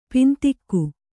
♪ pintikku